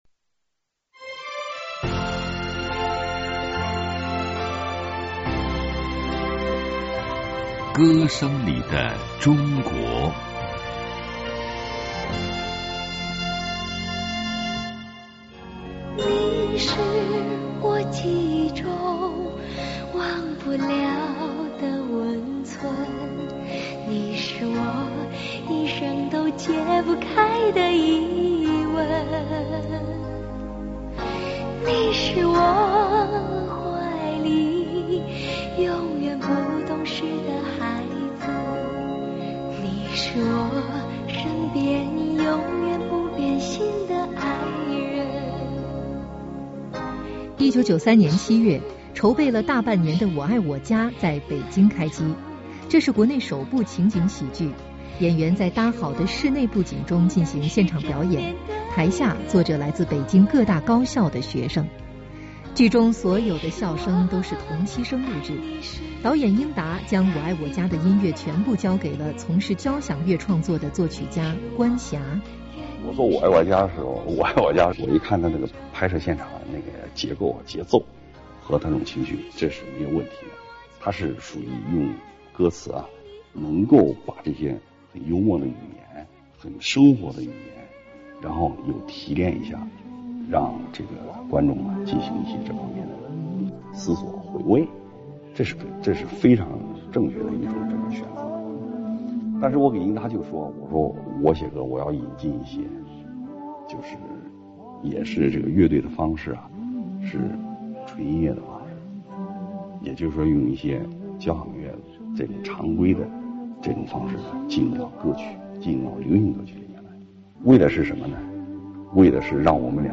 和幽默欢乐的剧情不同，《我爱我家》所有的歌曲都节奏舒缓，充满柔情，有的还略带忧伤。